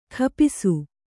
♪ khapisu